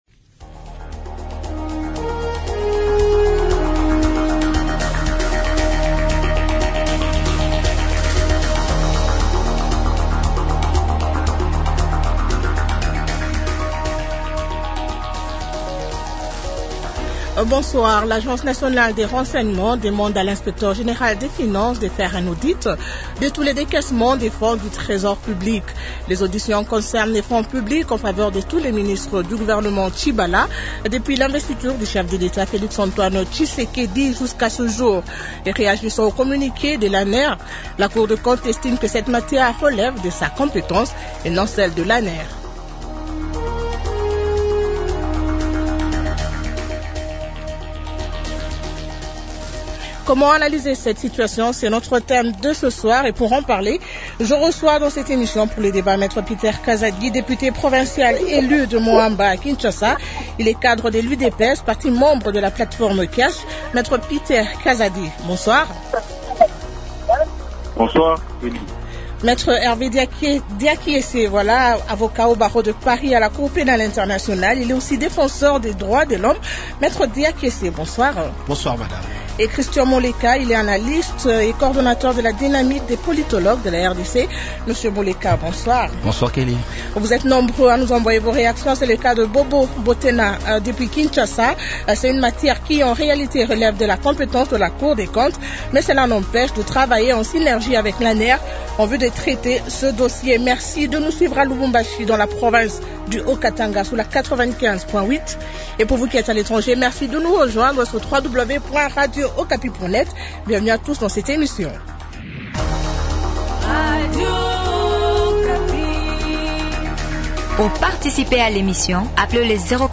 Comment analyser la requête de l'ANR ? Invités Me Peter Kazadi, Député provincial élu de Mont Amba à Kinshasa.